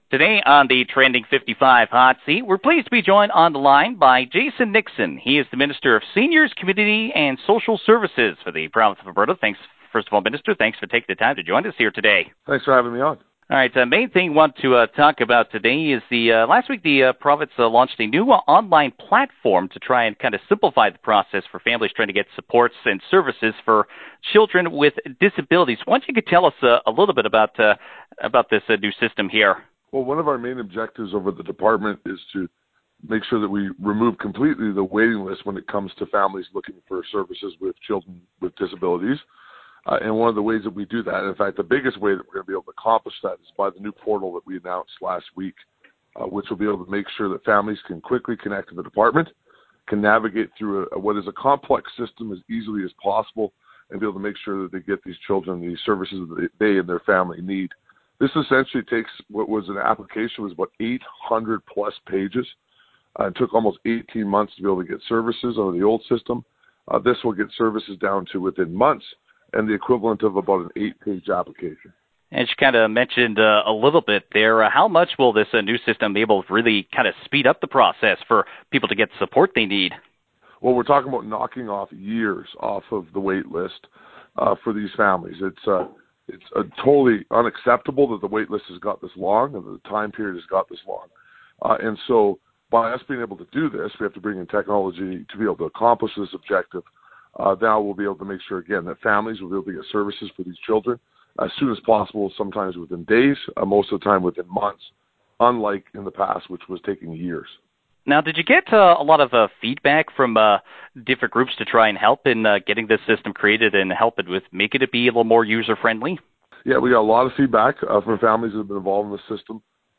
Jason Nixon, the Minister of Seniors, Community and Social Services, says they wanted to eliminate the waiting lists for families looking to get supports.
The full interview with the Minister can be found below.